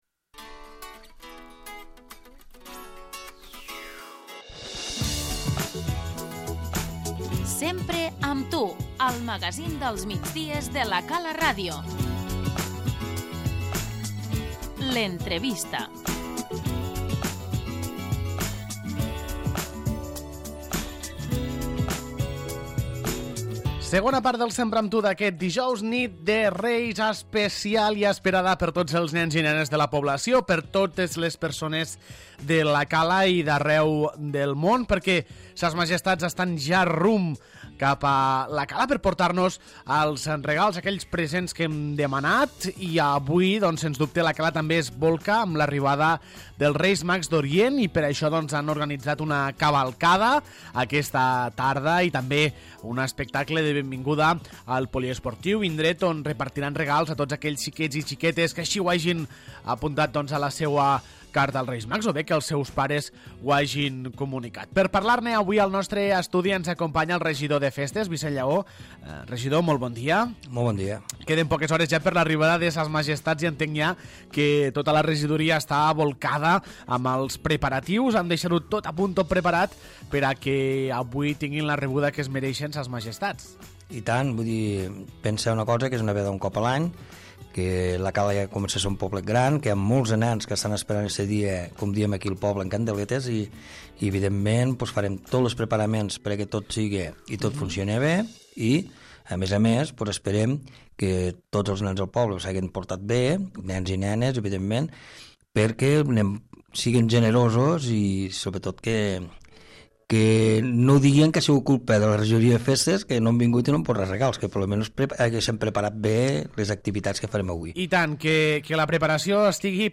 L'entrevista - SSMM Rei Baltasar i Vicenç Llaó, regidor de Festes
Abans de l'arribada al port pesquer, SSMM Rei Baltasar ha parlat amb nosaltres per explicar-nos com ho tenen tot a punt per a la nit més màgica de l'any. Amb la companyia del regidor de Festes, Vicenç Llaó, repassem com serà la cavalcada dels Reis i l'espectacle de benvinguda.